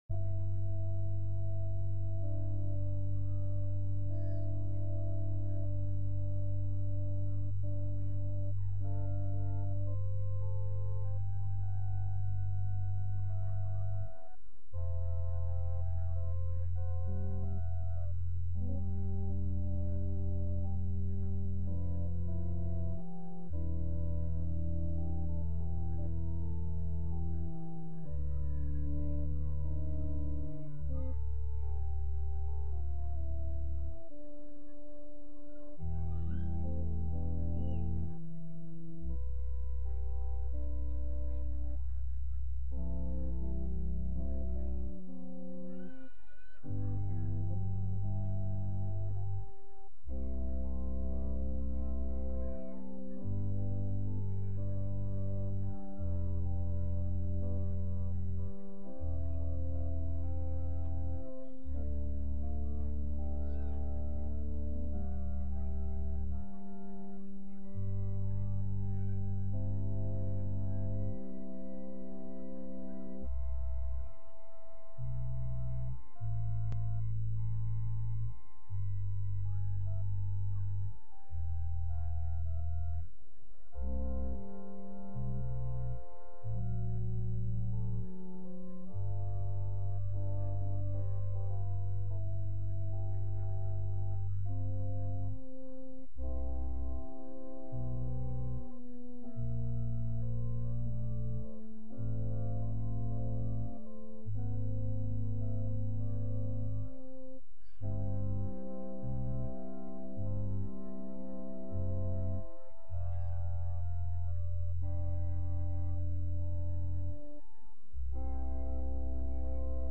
From Series: "Sunday Worship"
Sunday-Service-3-13-22.mp3